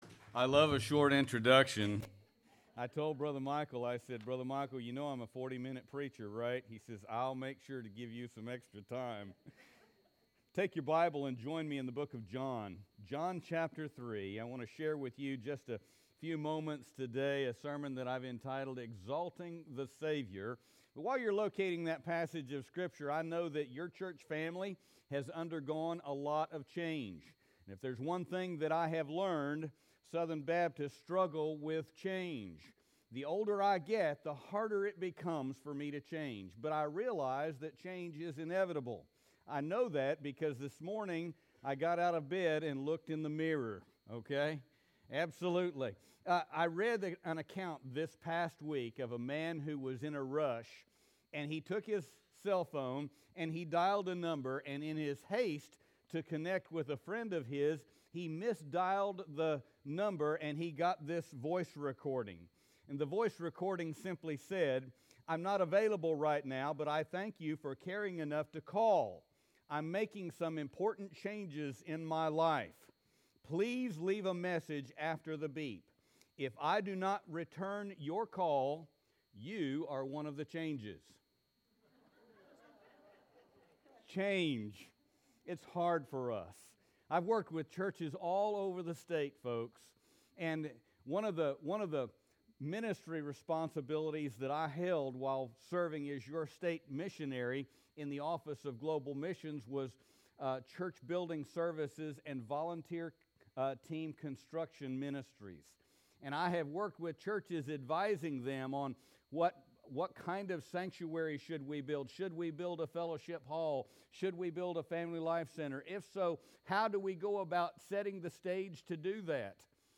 Autaugaville Baptist Church Sermons
Nov3Sermon2019.mp3